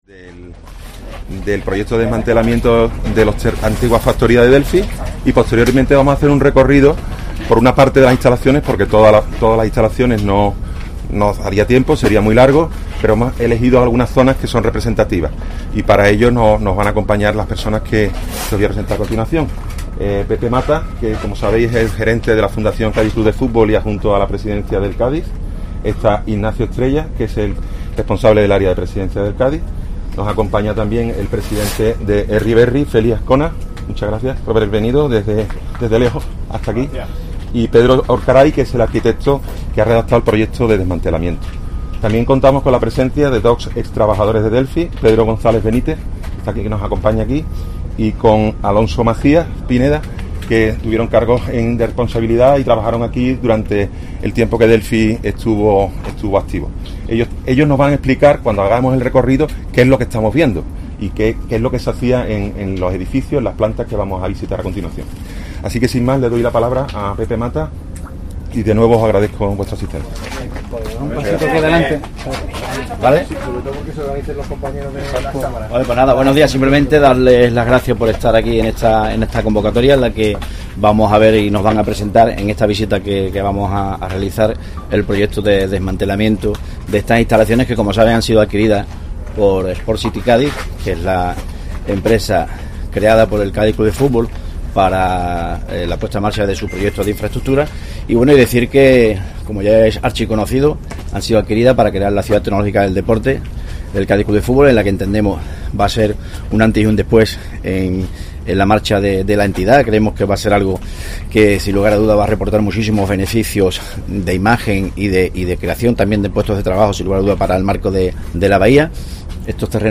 AQUÍ PUEDE ESCUCHAR LA RUEDA DE PRENSA OFRECIDA EN LAS PROPIAS INSTALACIONES: